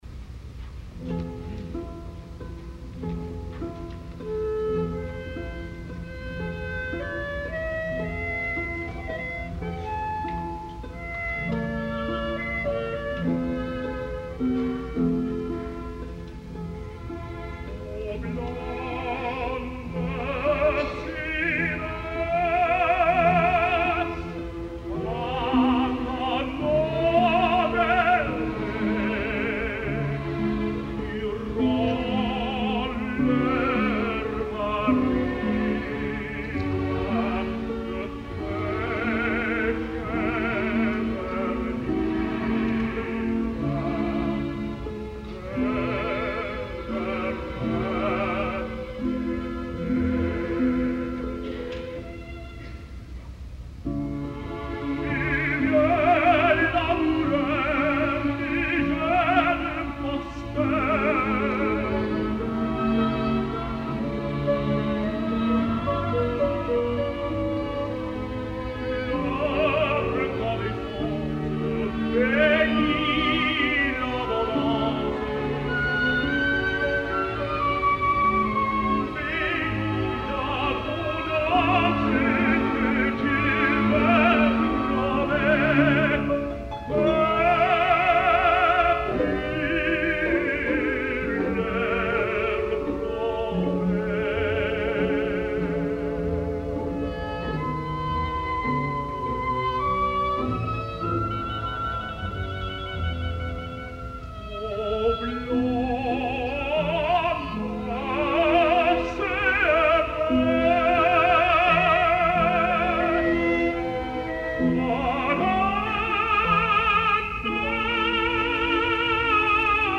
Sobre un acompanyament elegíac i camperol, iniciat per uns arpegis evocadors del arpa solista, Iopas, tenor líric, desplega la bellíssima melodia, amb un acompanyament deliciós. De mica en mica el cant es va ornamentant amb temibles ascensions a la zona aguda, que necessàriament haurà de ser abordada amb un combinat de veu de pit i notes en falset, com correspon a l’escola francesa.
En primer lloc escoltareu el debut al MET del tenor nord-americà Kenneth Riegel, en la famosa edició de Les Troyens de l’any 1973 , dirigida per Rafael Kubelik, que en properes edicions d’aquesta sèrie tindrem oportunitat de tornar-hi, doncs són uns Troyens per sucar-hi pa. La veu de Riegel no és especialment bonica, però el cant si, escolteu-lo i ja em direu.